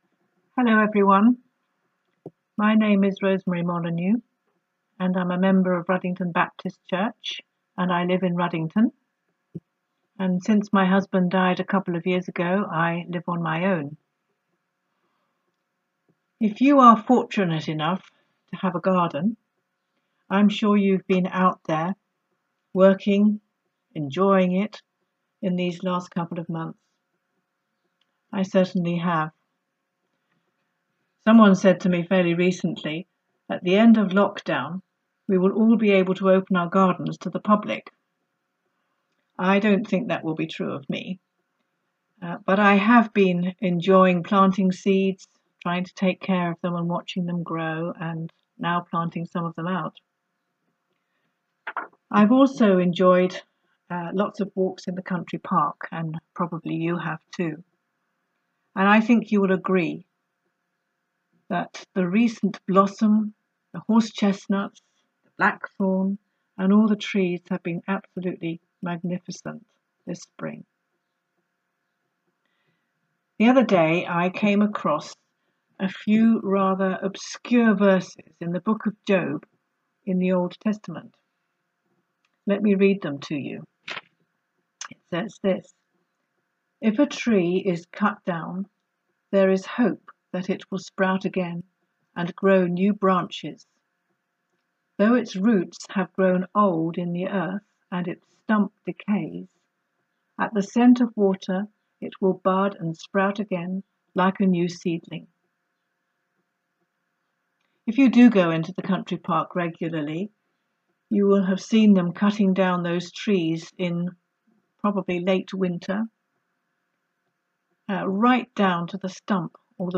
Weekly reflections